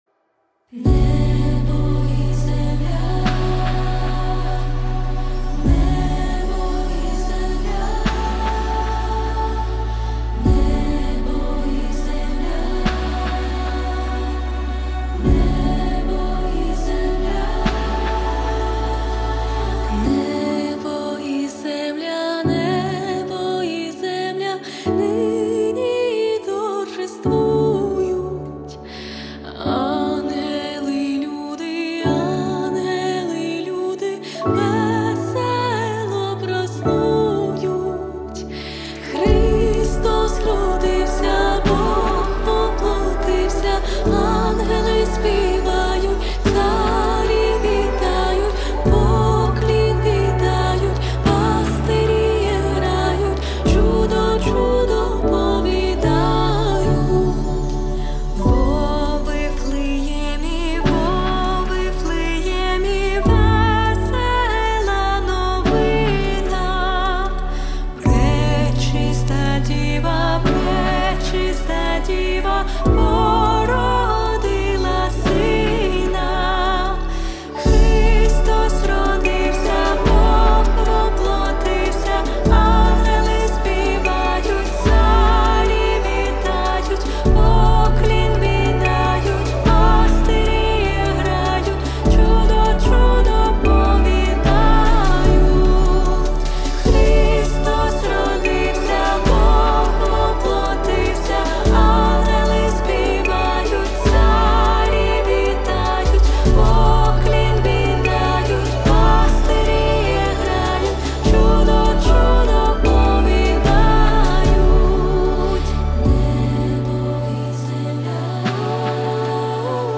320 просмотров 287 прослушиваний 19 скачиваний BPM: 76